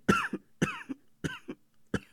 cough_2.ogg